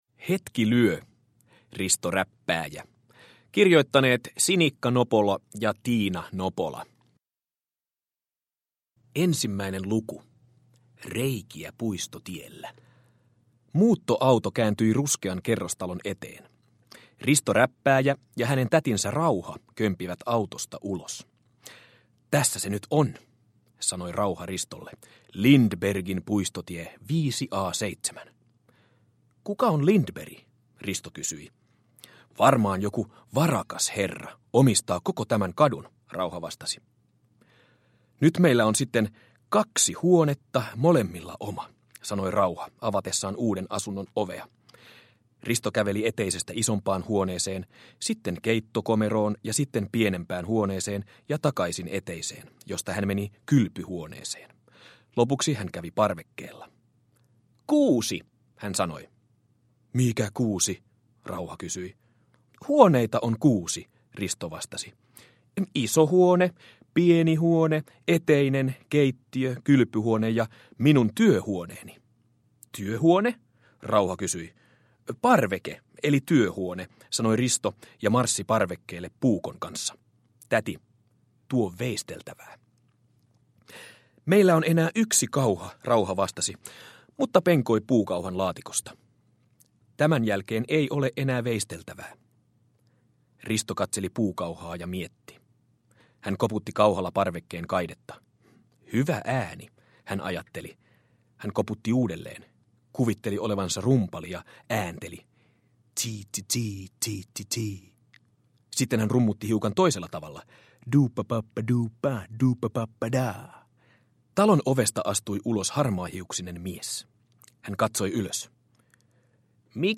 Hetki lyö, Risto Räppääjä (ljudbok) av Sinikka Nopola